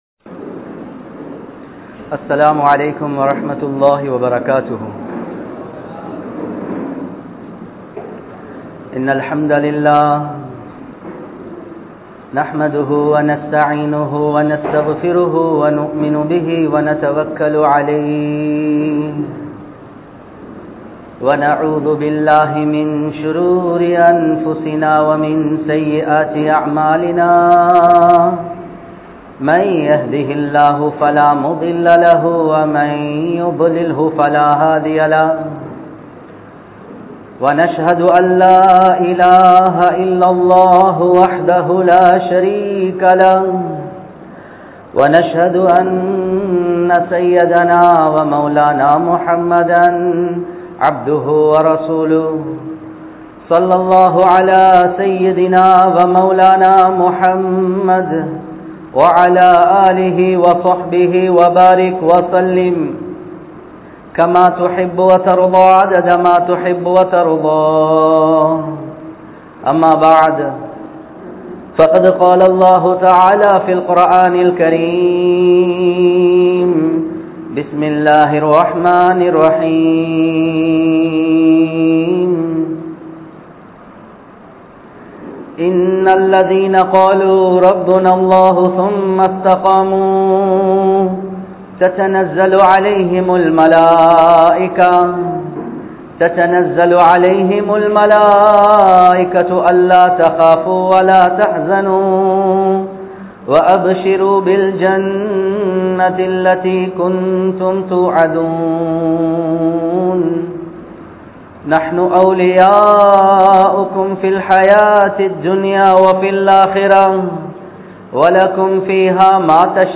Vaalifarhale! Paavaththai Vittu Vidungal (வாலிபர்களே! பாவத்தை விட்டுவிடுங்கள்) | Audio Bayans | All Ceylon Muslim Youth Community | Addalaichenai